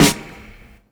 Medicated Snare 20.wav